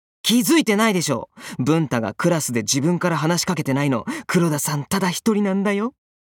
各キャラクターのサンプルボイスだけ先に掲載しておきます。